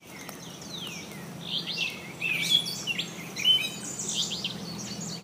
Alder Flycatcher, Sasso Tract, Rockaway, NJ, June 8, 2014